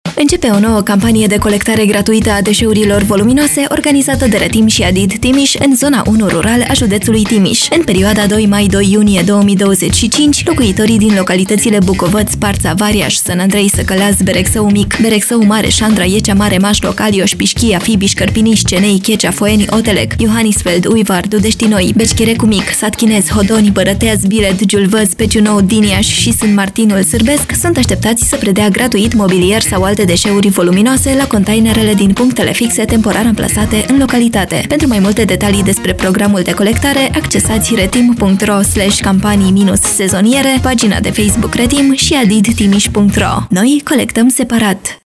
Spot-Radio-Voluminoase-Trim.-II-2025-Zona-1.mp3